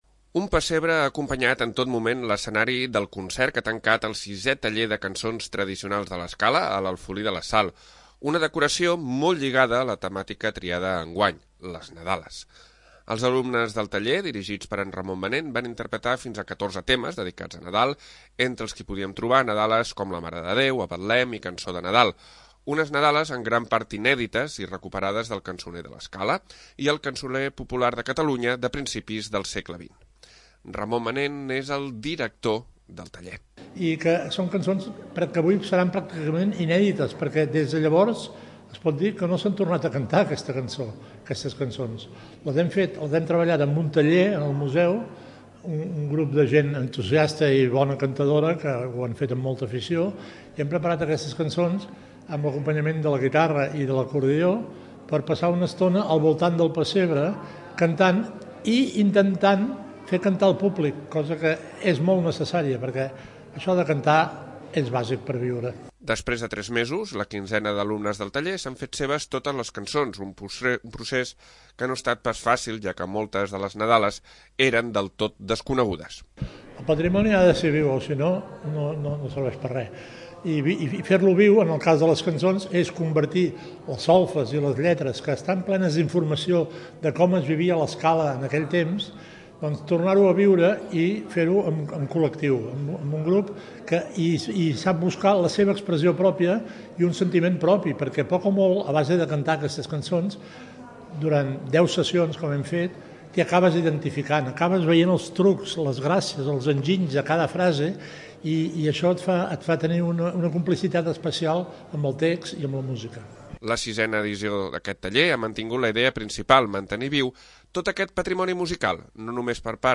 Els alumnes del sisè taller de cançons tradicionals de l'Escala han acabat el curs amb un gran concert a l'Alfolí, enguany dedicat a les cançons de Nadal. S'han interpretat 14 nadales recuperades del Cançoner d el'Escala, moltes d'elles inèdites.
No només per part del que s'hi han apuntat, sinó també del públic assistent al concert, que també ha acabat cantant bona part de les nadales.
El concert ha acabat amb N'es Nit de Nadal, una Nadala ben alegre on es va animar a tothom a cantar-la.